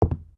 sounds / material / human / step / wood04gr.ogg